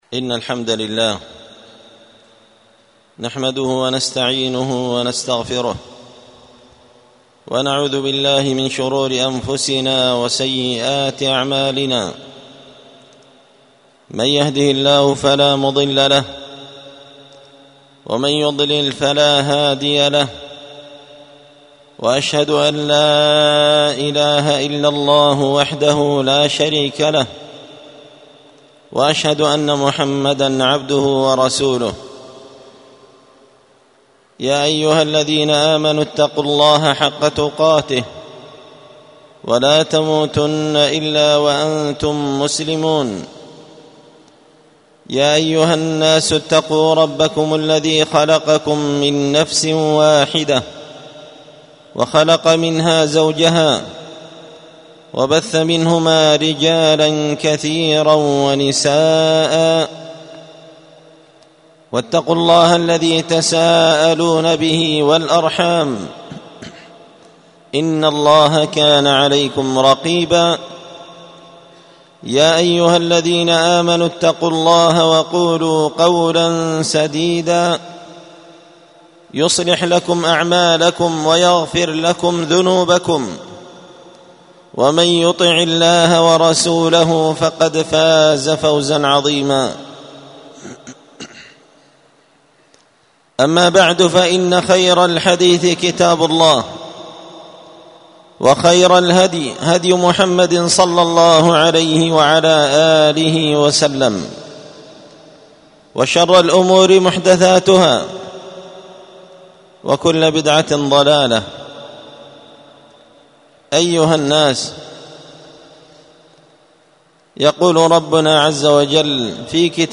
الجمعة 8 شعبان 1446 هــــ | الخطب والمحاضرات والكلمات | شارك بتعليقك | 72 المشاهدات
ألقيت هذه الخطبة بدار الحديث السلفية بمسجد الفرقان قشن -المهرة-اليمن تحميل